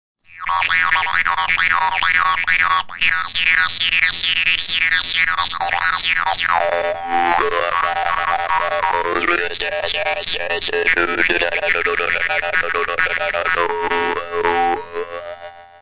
Die vietnamesischen Maultrommeln zeichnen sich durch ihre einfache Spielbarkeit, ihren schönen, obertonreichen Klang und den günstigen Preis aus.
Dabei erinnert ihr Sound an elektronische Klänge analoger Synthesizer – ganz ohne Technik.
Hörprobe Dan Moi Standard 3: